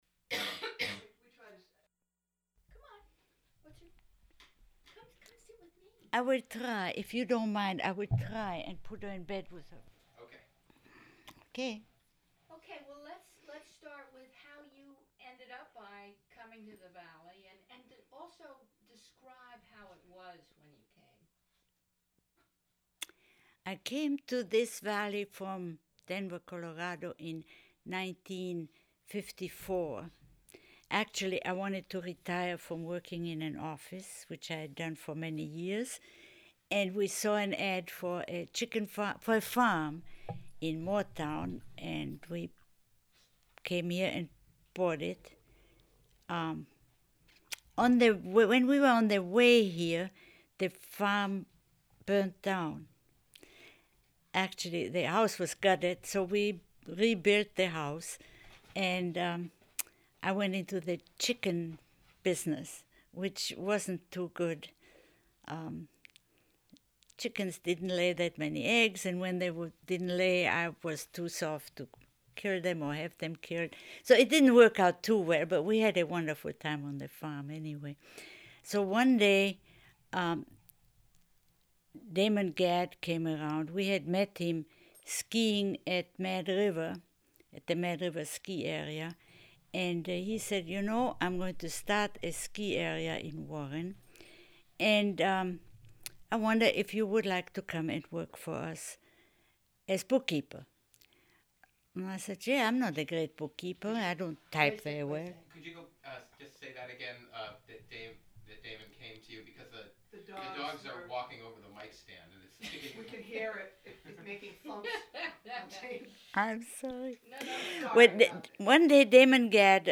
Interview
sound cassette (DAT)